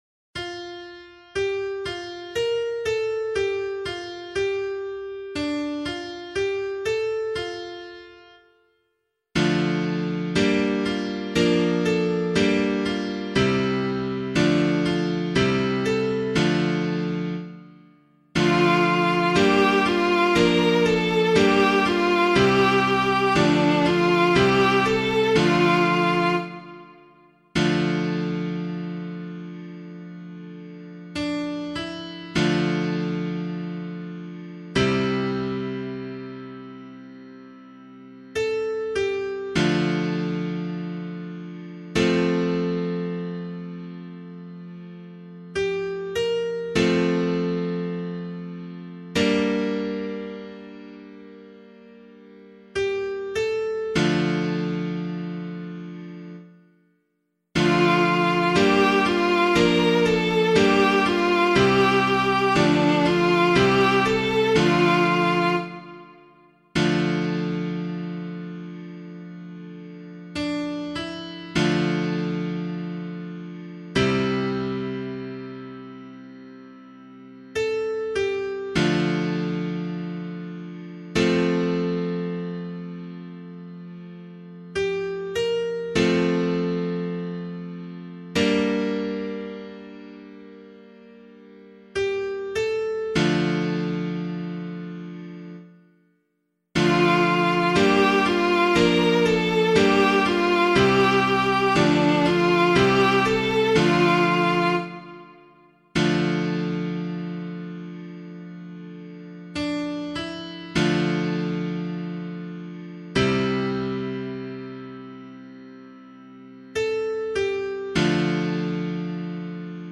010 Epiphany Psalm [LiturgyShare 1 - Oz] - piano.mp3